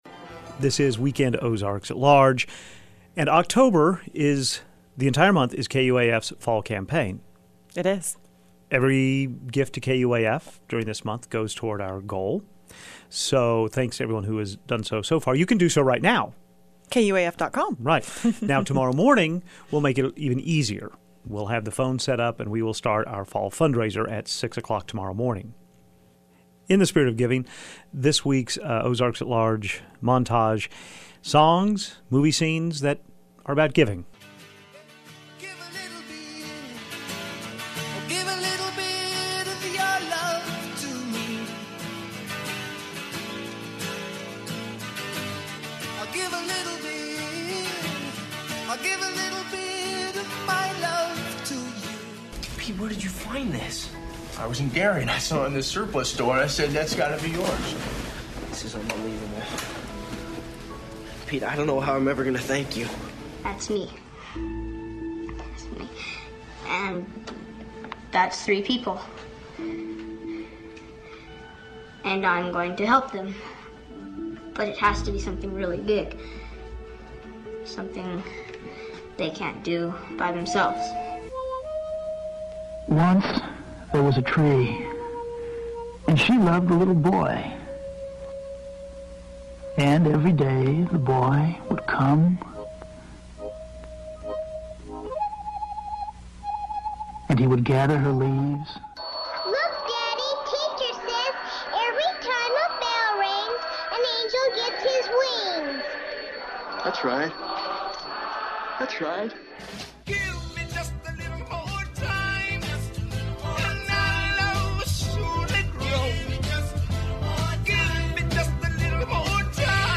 Here are the elements of our montage of giving scenes and songs.